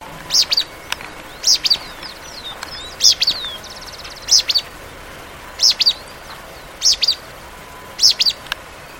Uí-pi (Synallaxis albescens)
Ejemplar que vocalizaba en un pequeño grupo de acacias negras (Gleditsia triacanthos), ubicadas sobre un camino de tierra.
Partido de Saladillo, Provincia de Buenos Aires.
Nome em Inglês: Pale-breasted Spinetail
Detalhada localização: Camino de tierra en campo abierto
Certeza: Observado, Gravado Vocal
Pijui-cola-parda.mp3